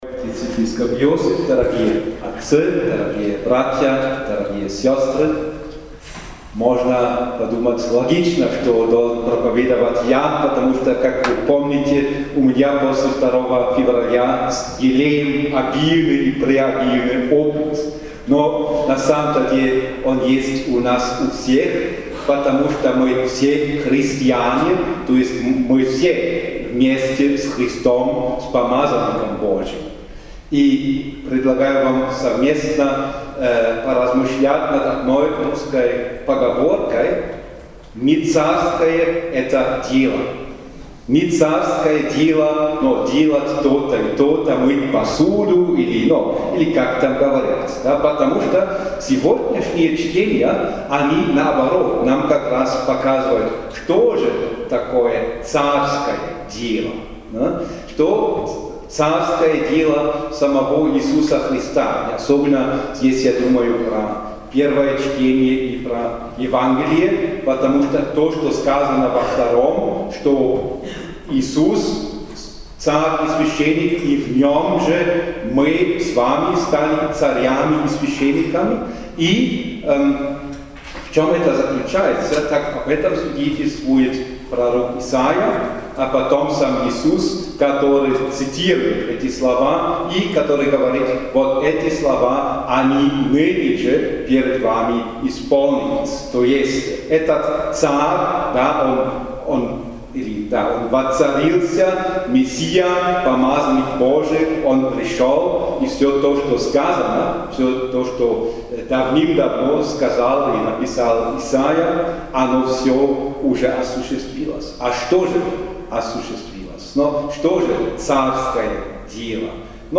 Святая Месса благословения елеев в главном храме Преображенской епархии (+ ФОТО)
Утром 15 апреля 2025 г. в Кафедральном соборе Преображения Господня Правящий епископ владыка Иосиф Верт в сослужении со вспомогательным епископом владыкой Штефаном Липке и духовенством Преображенской епархии совершил Литургию освящения мира и благословения елеев (Missa Chrismatis).